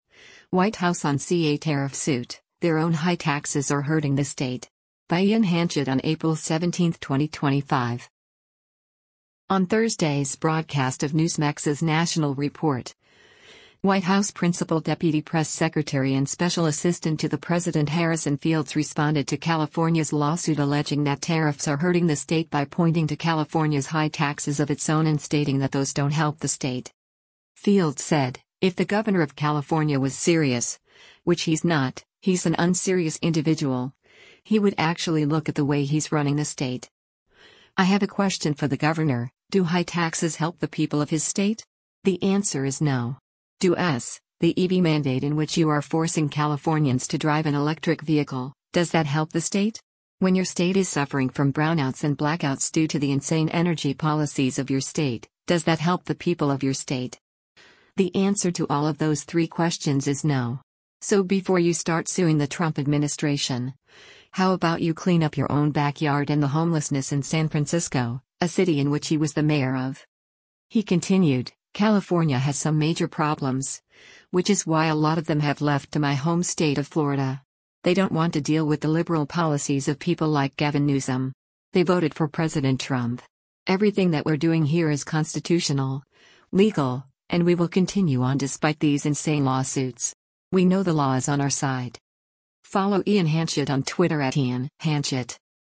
On Thursday’s broadcast of Newsmax’s “National Report,” White House Principal Deputy Press Secretary and Special Assistant to the President Harrison Fields responded to California’s lawsuit alleging that tariffs are hurting the state by pointing to California’s high taxes of its own and stating that those don’t help the state.